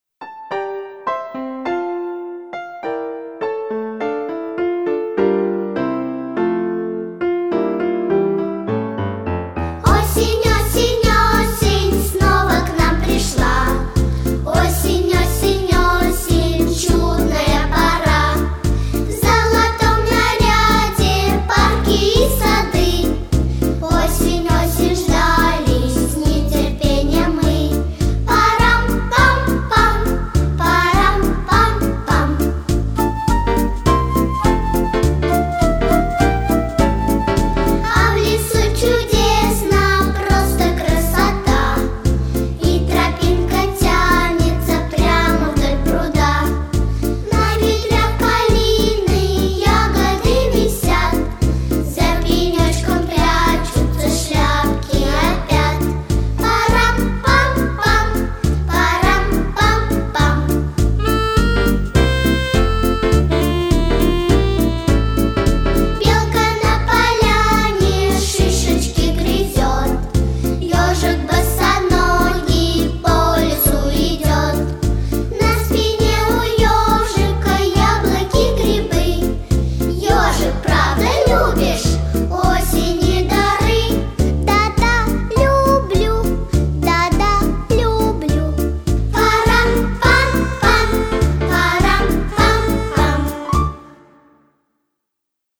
Главная / Песни для детей / Песни про осень